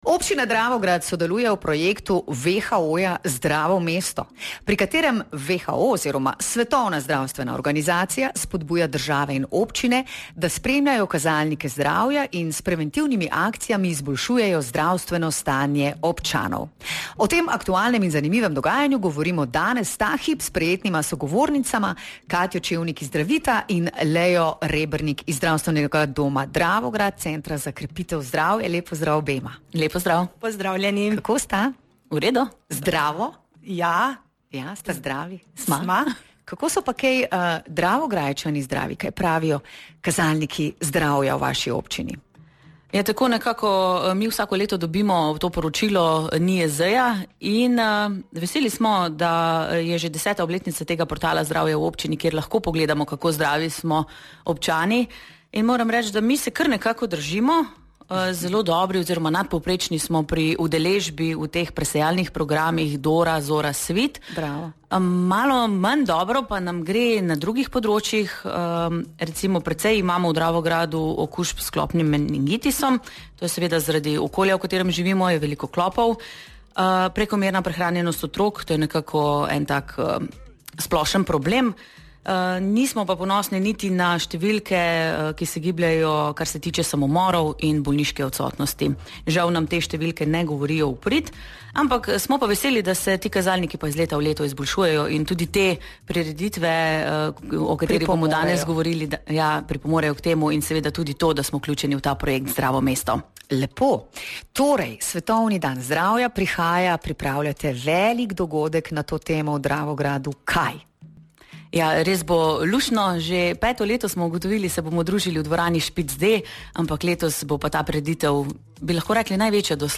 Občina Dravograd in Center za krepitev zdravja Dravograd vabita na osrednjo prireditev ob dnevu zdravja, in sicer v ponedeljek, 7. aprila med 16. in 19. uro v dvorano Špic D. Veliko brezplačnih aktivnosti, meritev in idej za zdravo prehrano čaka vse, ki jim je mar za svoje zdravje. Več v pogovoru